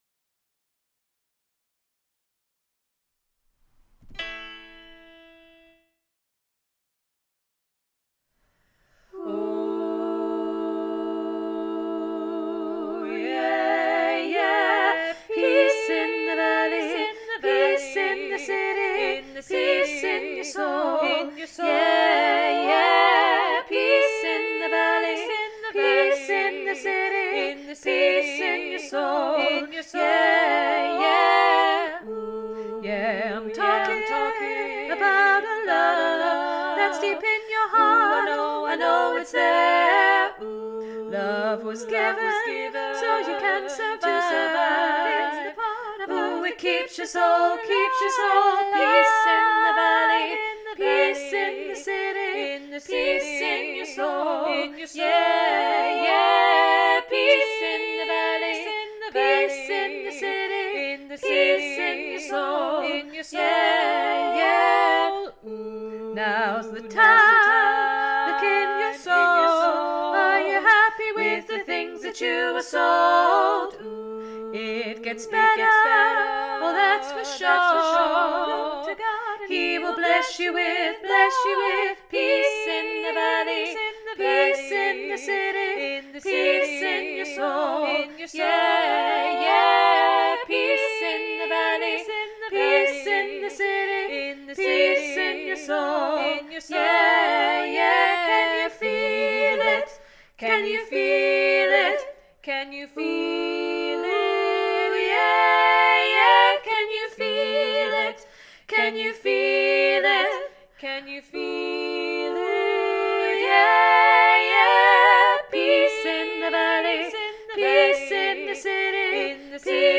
Peace 3VG Revamp NO TENOR